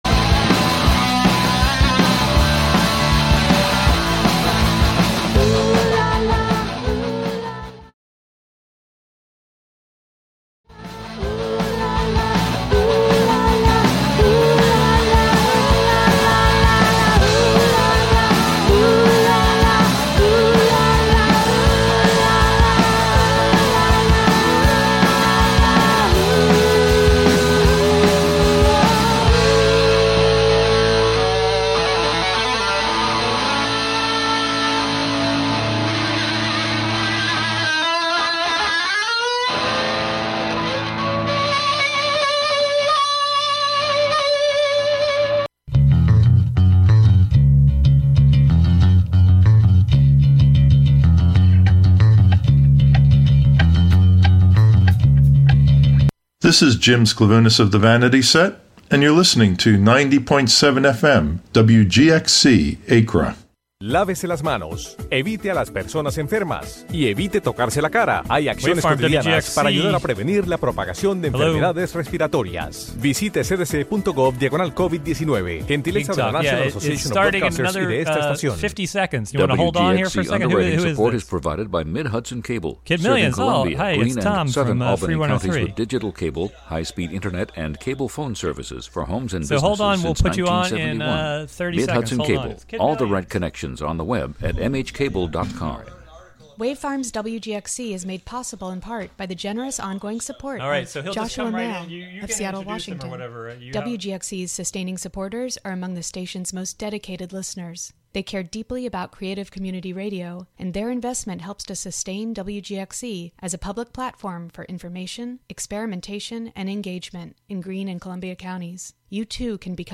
In this current era of indeterminacy, it's important to appreciate the history of live music and talk about it on the radio. Callers share their experience 'on the gig' and receive advice from the expert hosts.